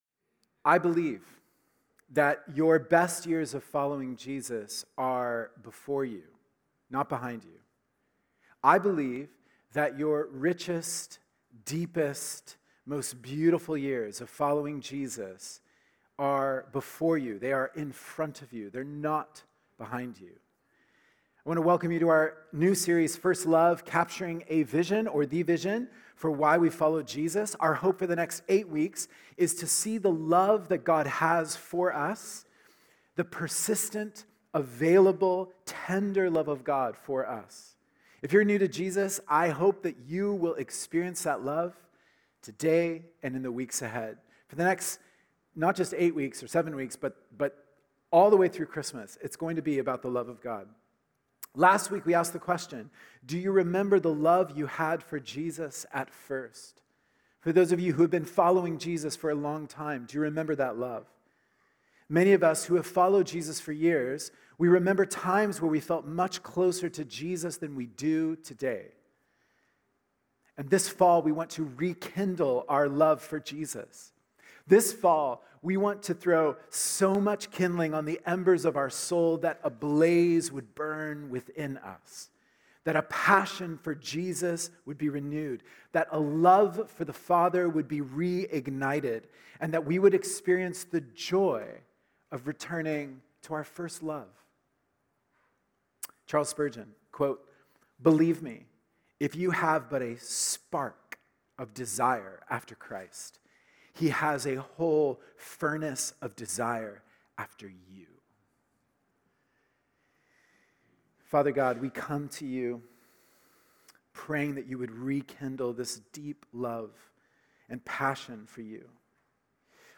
Walnut Grove Sermons | North Langley Community Church